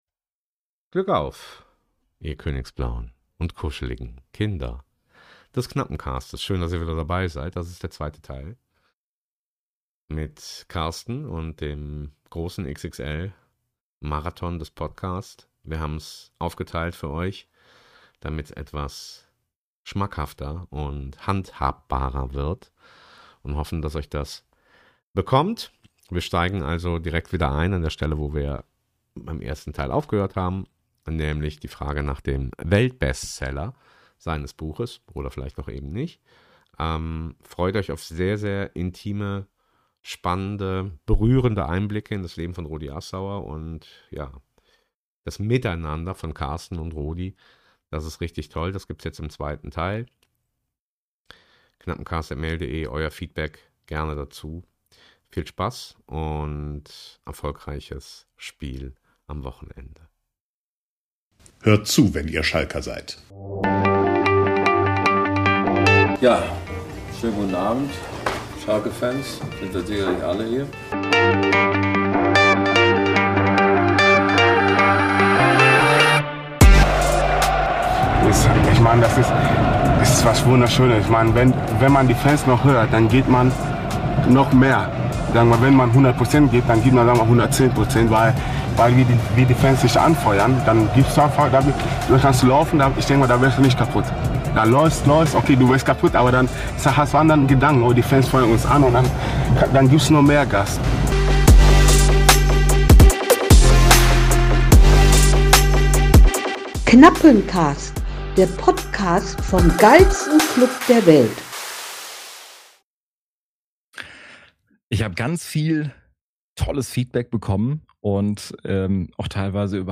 Teil 2 des großen Interviews